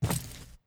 Footstep_Concrete 03.wav